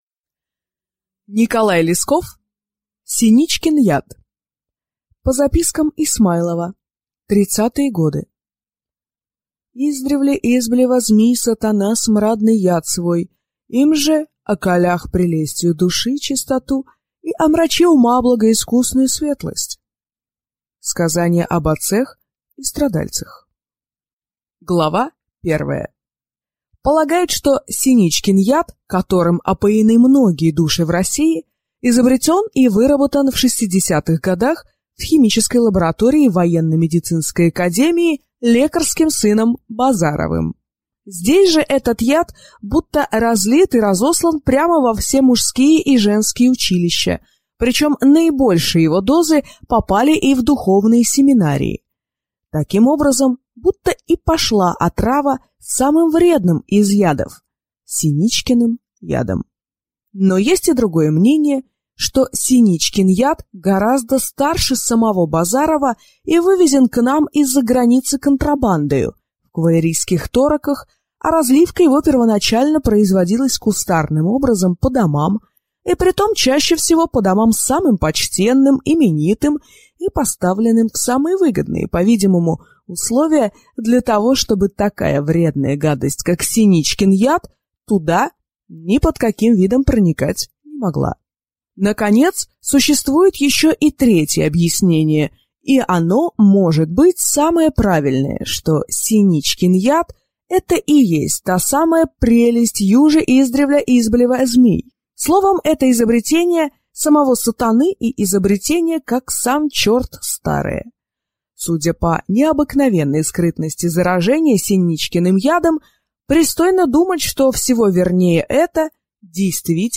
Аудиокнига Сеничкин яд | Библиотека аудиокниг